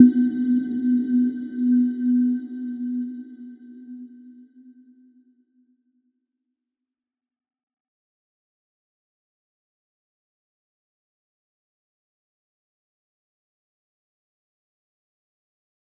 Little-Pluck-B3-f.wav